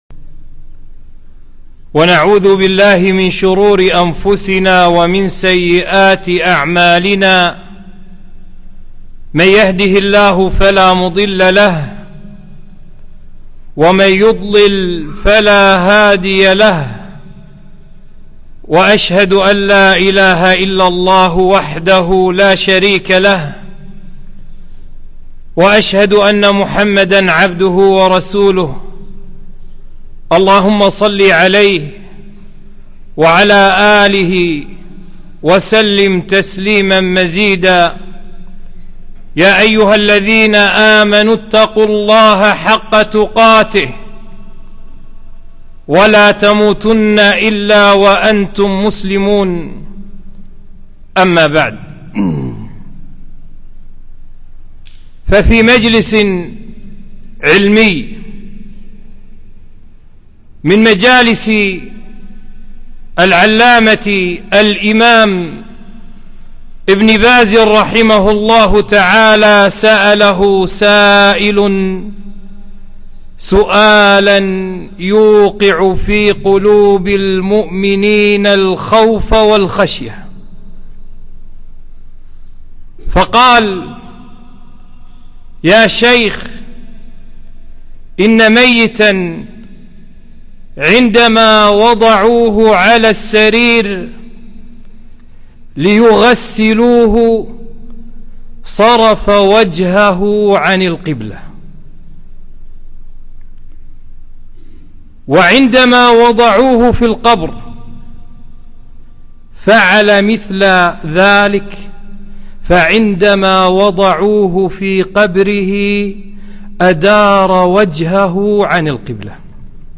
خطبة - خطورة التهاون في أداء الصلاة في وقتها،